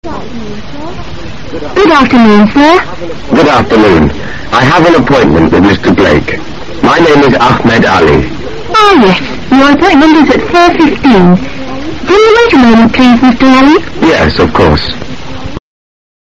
在线英语听力室An Appointment的听力文件下载,英语经典听力对话-在线英语听力室